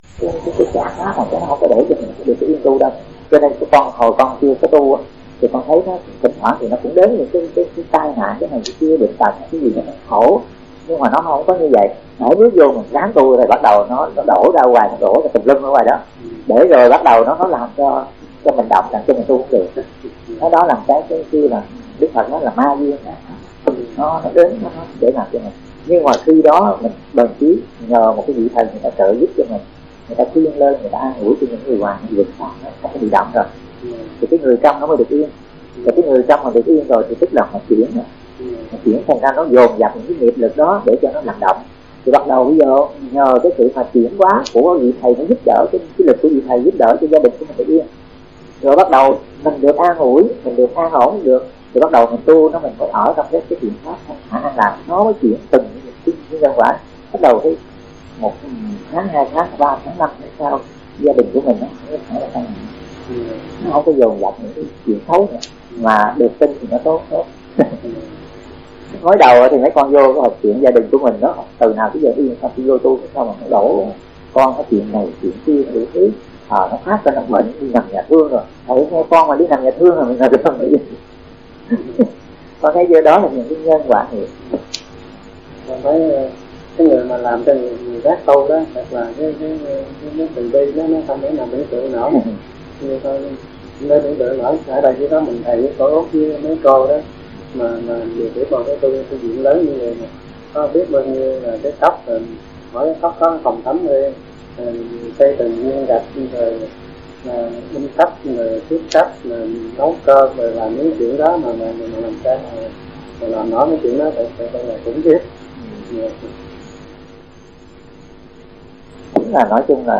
Người nghe: Phật tử